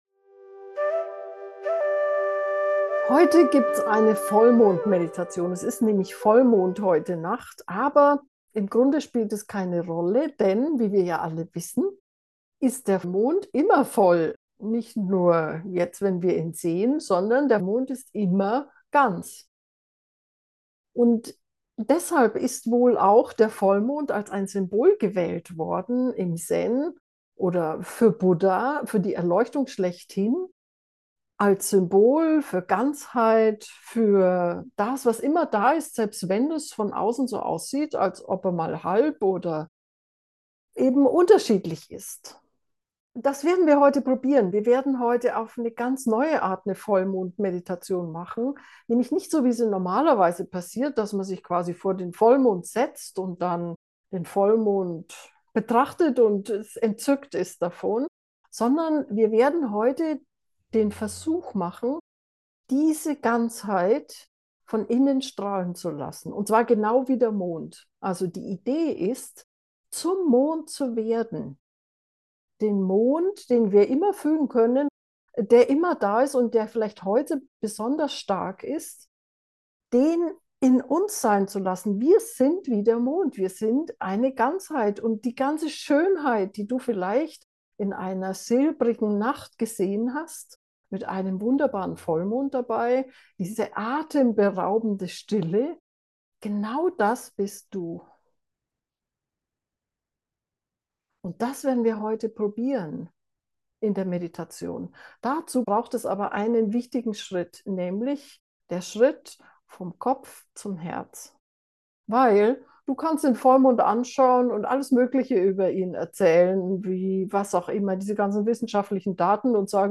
Herzmeditationen